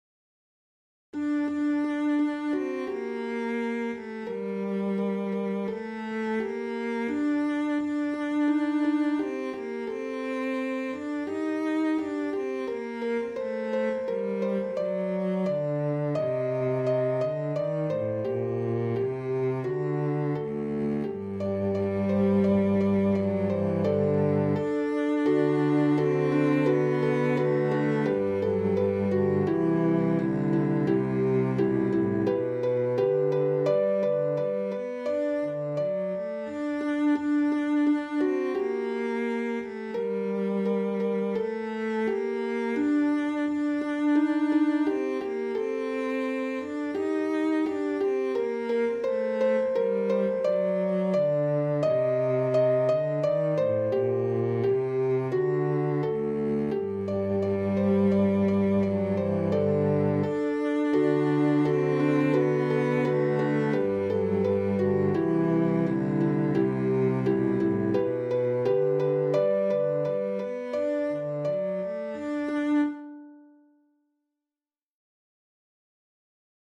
SA OR SB OR TB (2 voices mixed OR equal) ; Full score.
Sacred.
Tonality: G minor